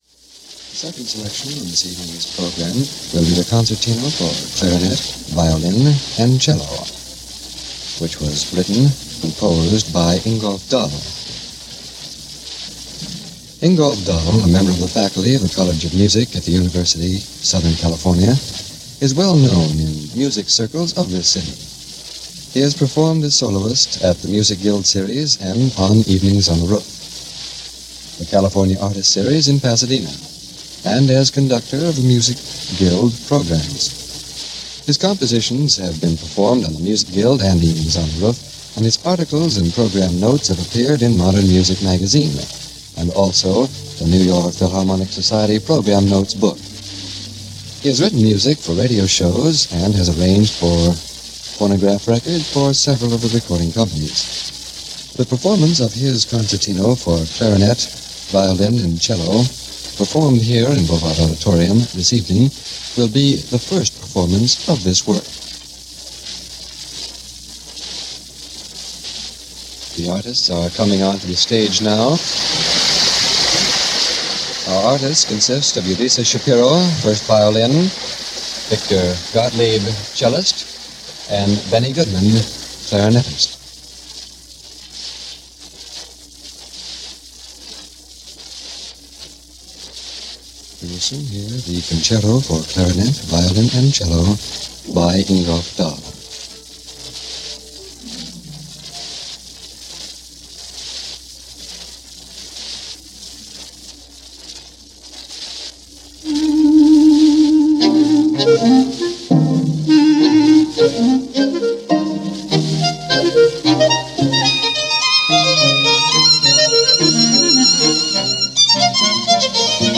clarinet
cello – recorded at Bovard Auditorium, USC
recorded during a live broadcast